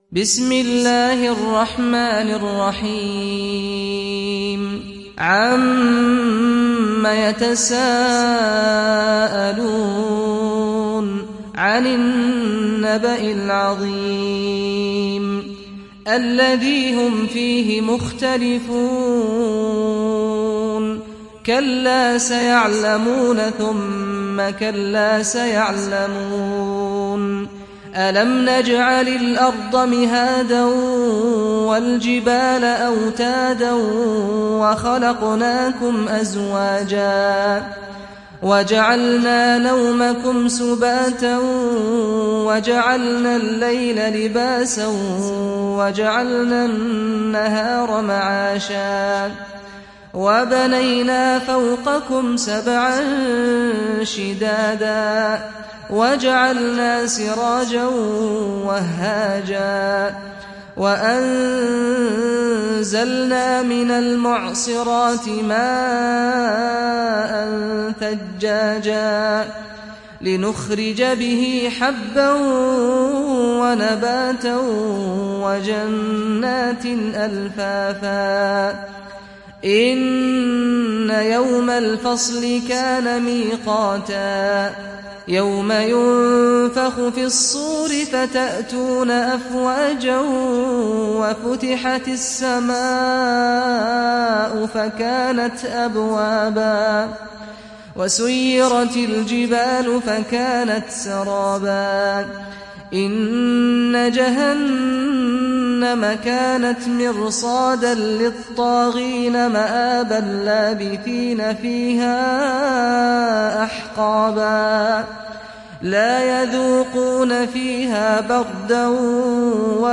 Nebe Suresi İndir mp3 Saad Al-Ghamdi Riwayat Hafs an Asim, Kurani indirin ve mp3 tam doğrudan bağlantılar dinle